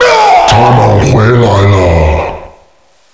zombie_coming_01.wav